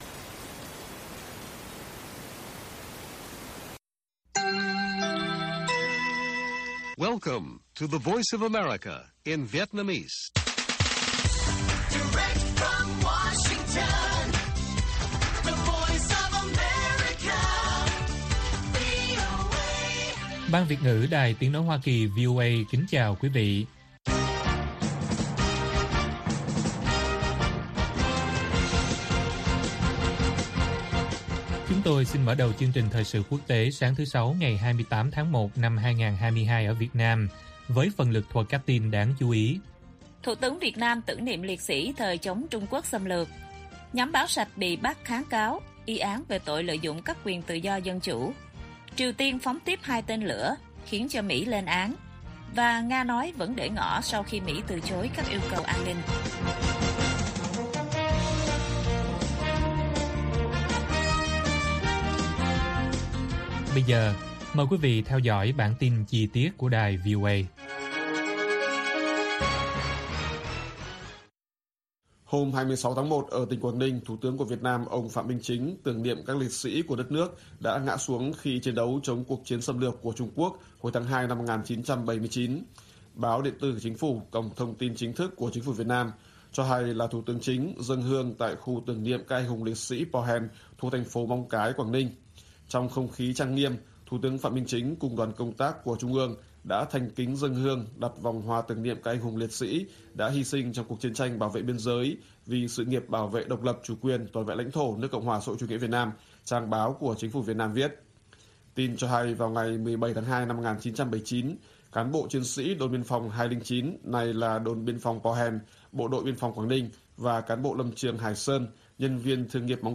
Bản tin VOA ngày 28/1/2022